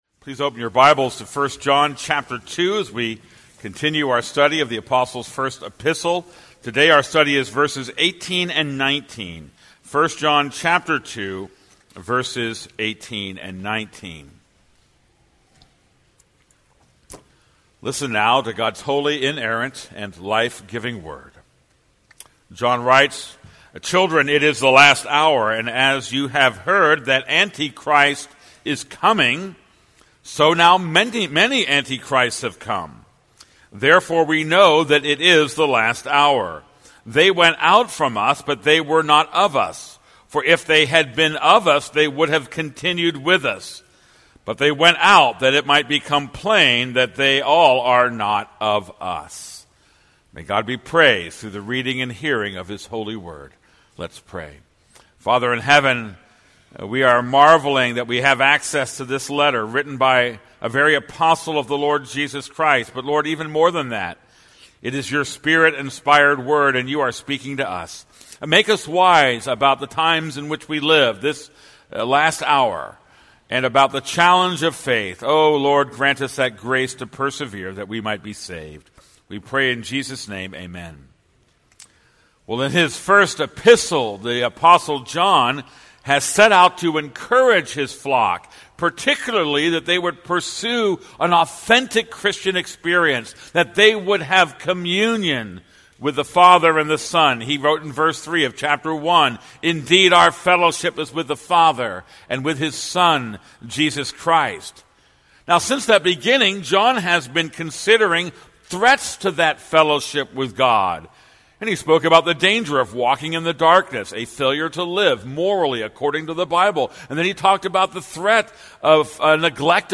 This is a sermon on 1 John 2:18-19.